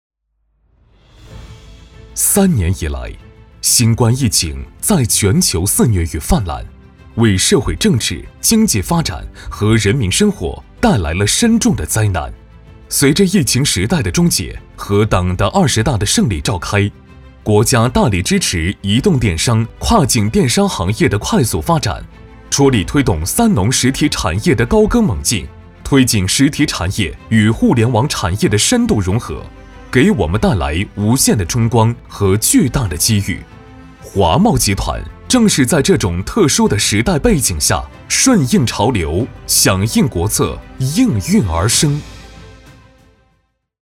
男81号